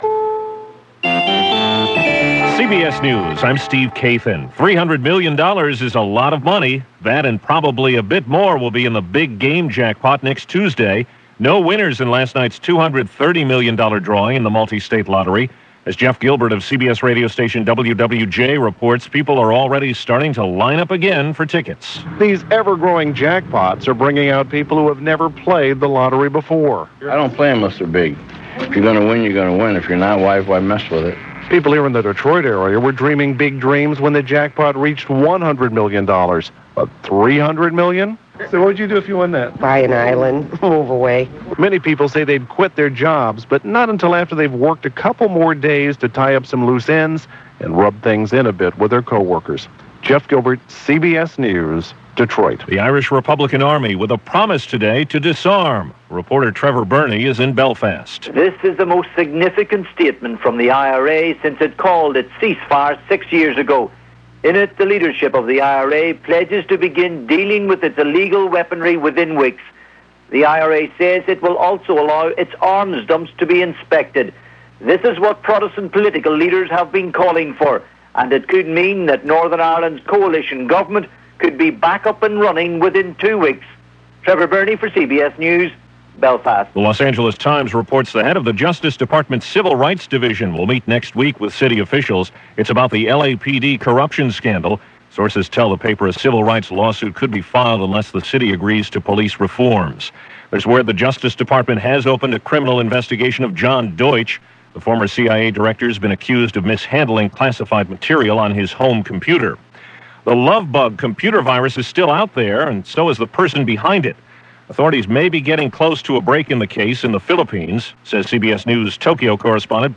And that’s just a small slice of what went on, this May 9, 2000 via CBS Radio News on The Hour.